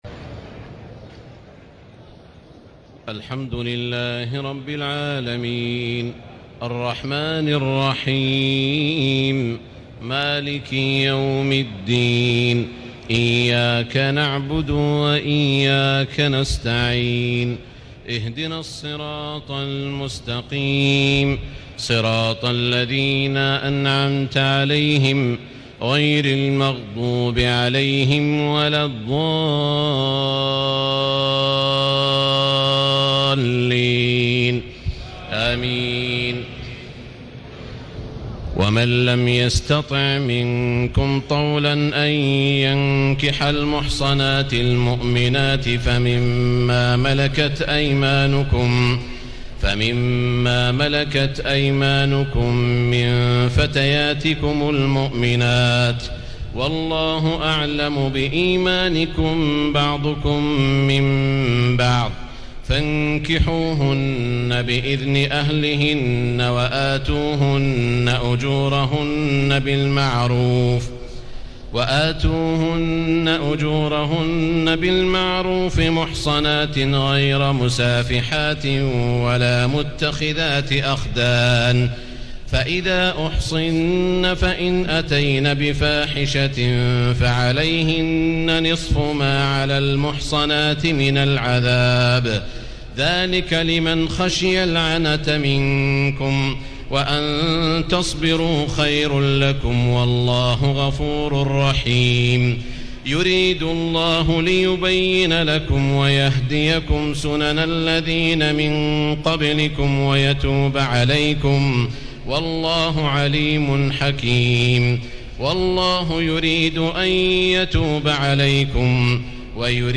تهجد ليلة 25 رمضان 1436هـ من سورة النساء (25-99) Tahajjud 25 st night Ramadan 1436H from Surah An-Nisaa > تراويح الحرم المكي عام 1436 🕋 > التراويح - تلاوات الحرمين